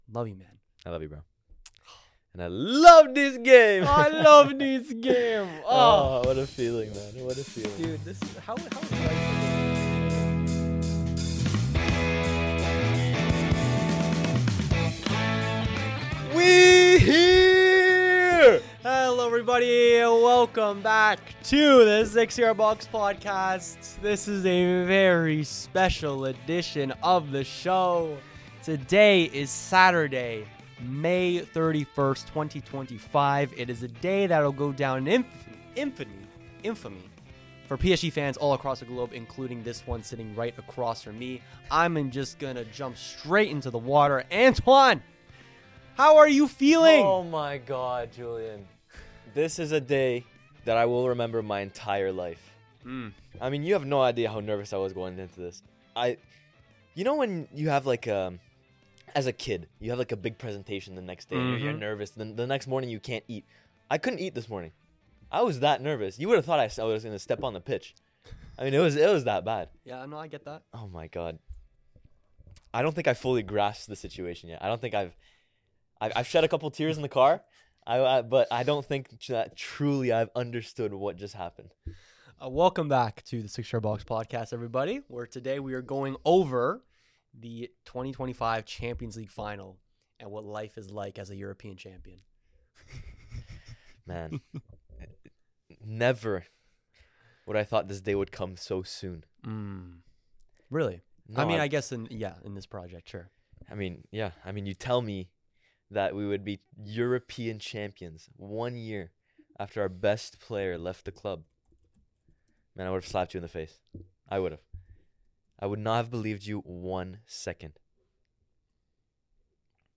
The CL semi-final first-leg matchups are now through, which saw PSG pick up the away win and Barca and Inter ending in a stalemate. The lads dissect, analyze and yell about what was a fascinating week of footy.